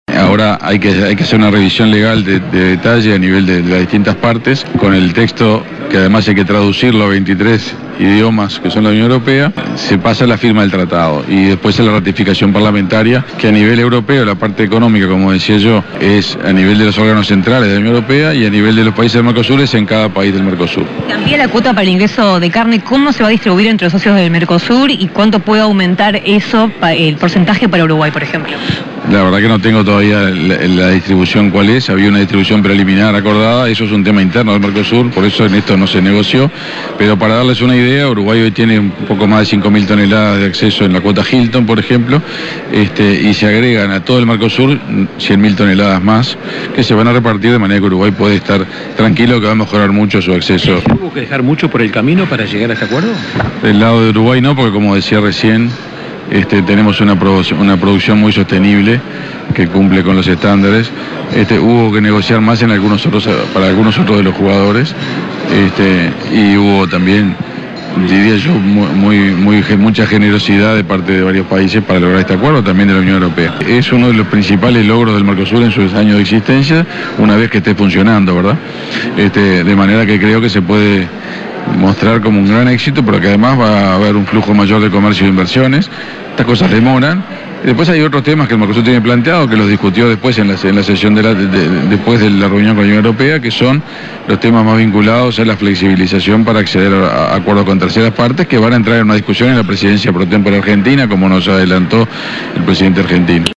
El Canciller Uruguayo Omar Paganini, destacó mayor flujo comercial que permitirá el tratado con Unión Europea